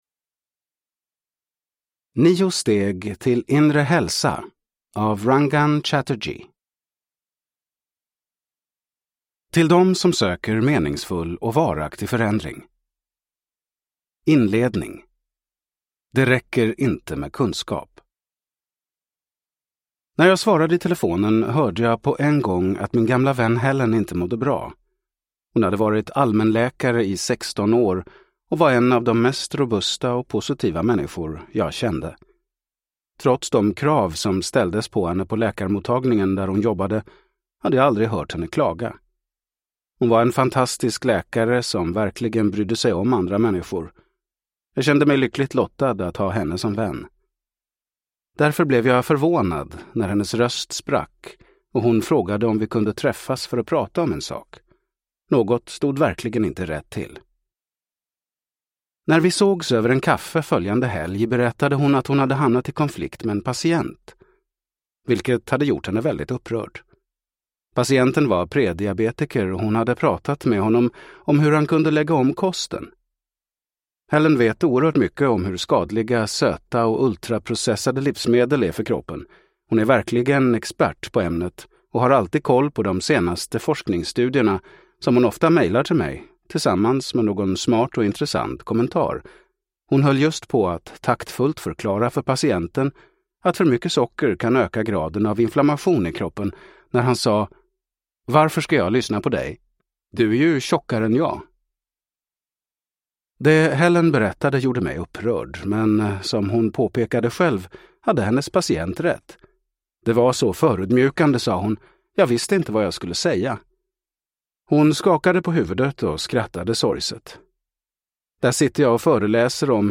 9 steg till inre hälsa : så skapar du varaktig förändring – Ljudbok